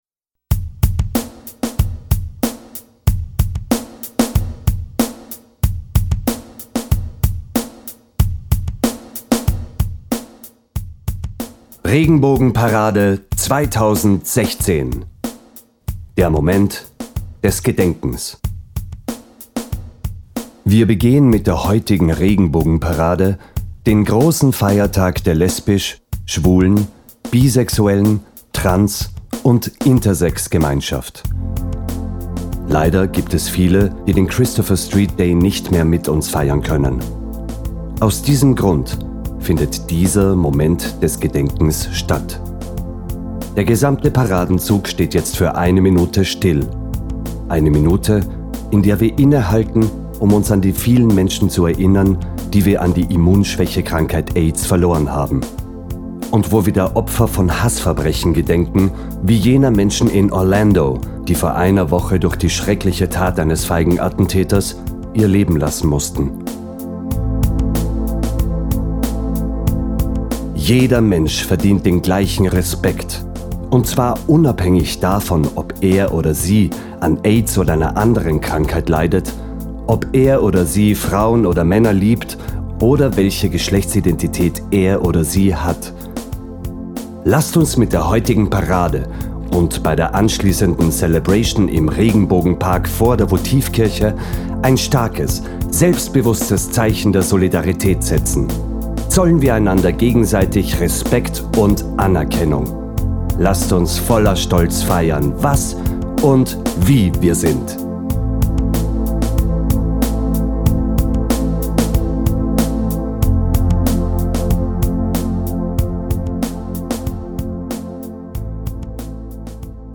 Auch heuer findet wieder der Moment des Gedenkens statt: Der Paradenzug hält an, und Wägen mit Tonanlagen spielen folgende Durchsage ab: